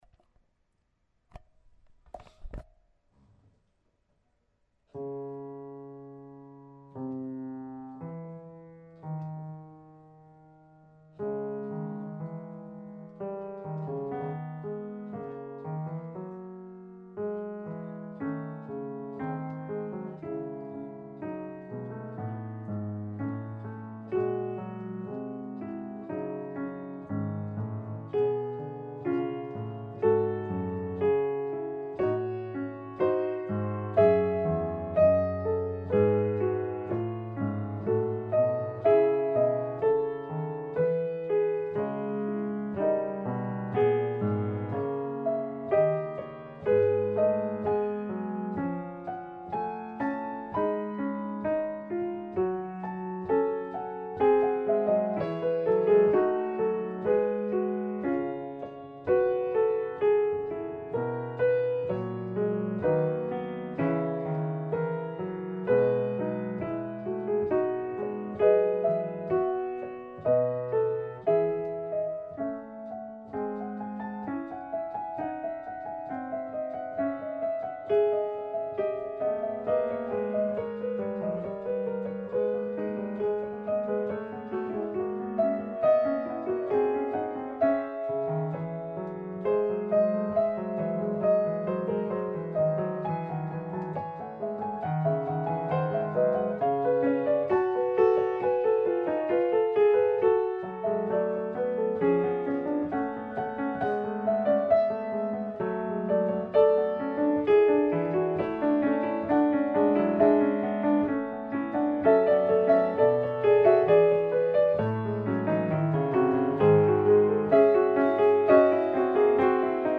fuga che allego qui è la n. 4 del Clavicembalo Ben Temperato, I libro; è in DO# minore e le voci sono 5: poiché il tema è di sole 4 note, lo si sente ricorrere spesso. Ho scelto questa per la sua particolare bellezza nonostante la notevole complessità e chiedo subito perdono per la mia esecuzione e registrazione casalinghe!